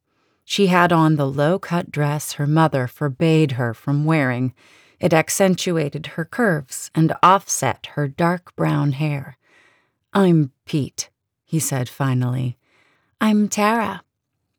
I used the ACX Mastering Macro 3.6 for those 3 steps but found my de-clicker settings increase the peak level so I moved the normalization & limiter to after the de-click.
It sounds good to my ear but would love feedback before making a macro and processing the rest.
Noise Gate to reduce breath Filter Curve EQ (settings are from 3.6 ACX Mastering Macro) De-clicker (I run 1 pass two times; 2 passes in one go slows or crashes larger files) Desibiliator Loudness Normalization & Limiter (settings are from 3.6 ACX Mastering Macro)
I purposely haven’t gone through and corrected little things I can still hear.